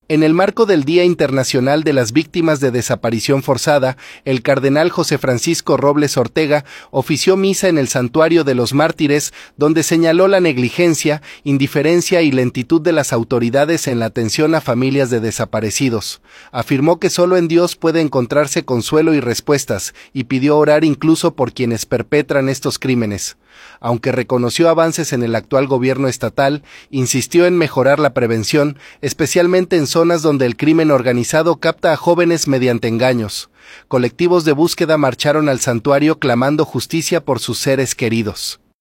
En el marco del Día Internacional de las Víctimas de Desaparición Forzada, el cardenal José Francisco Robles Ortega ofició misa en el Santuario de los Mártires, donde señaló la negligencia, indiferencia y lentitud de las autoridades en la atención a familias de desaparecidos. Afirmó que solo en Dios puede encontrarse consuelo y respuestas, y pidió orar incluso por quienes perpetran estos crímenes. Aunque reconoció avances en el actual gobierno estatal, insistió en mejorar la prevención, especialmente en zonas donde el crimen organizado capta a jóvenes mediante engaños.